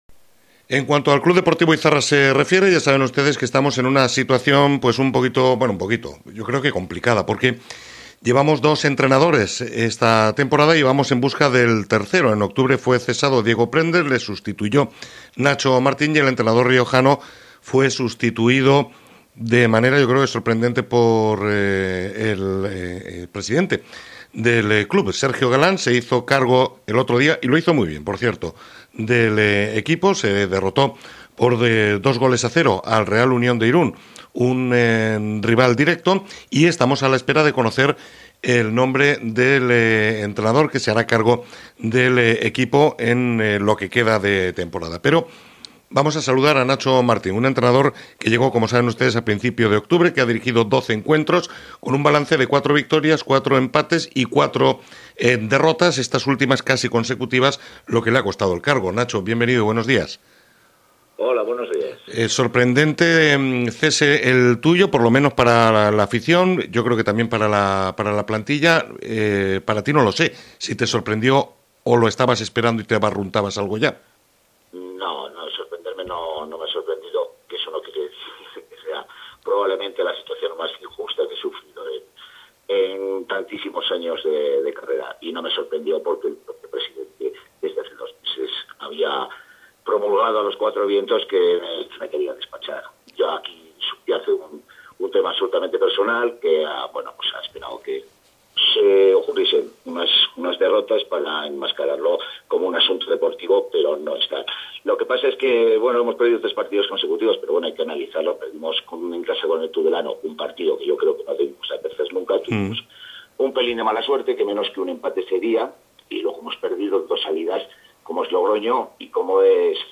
explicó el entrenador en una entrevista en Onda Cero Navarra